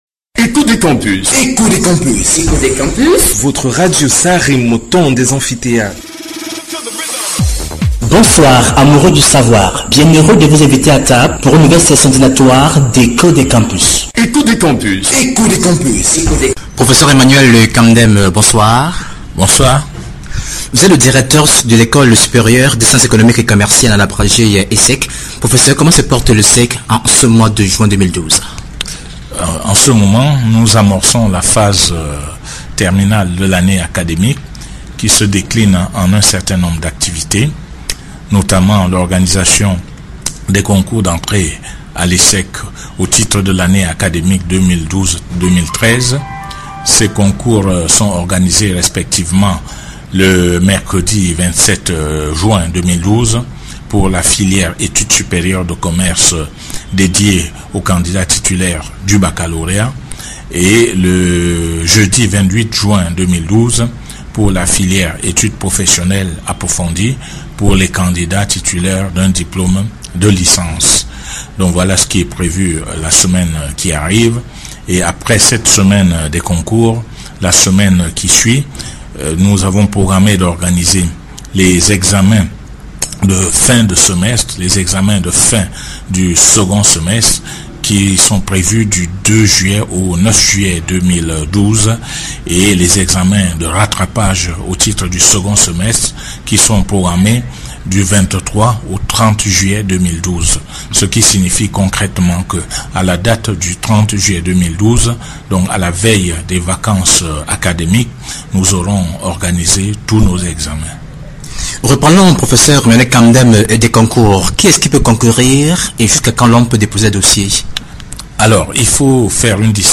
Interventions médiatiques
interview_radio_campus_1.mp3